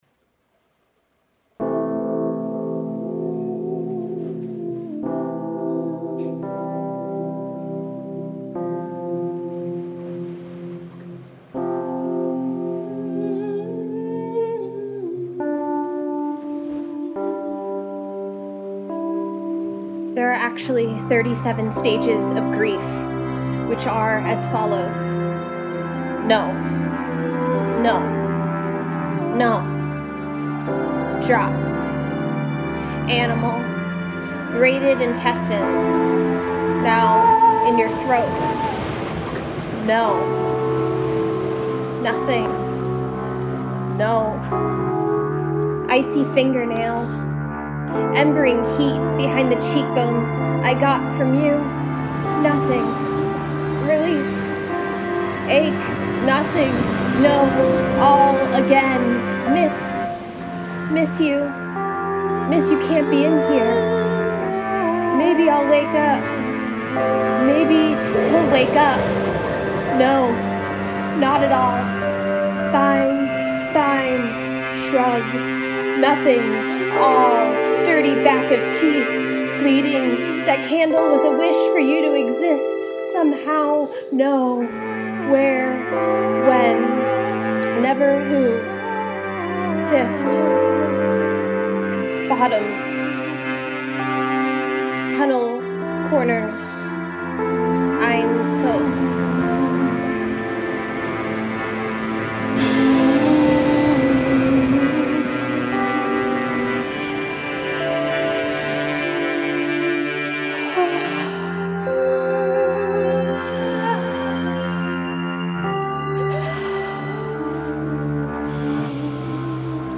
This piece is a sound poetry piece written and composed by while in quarantine.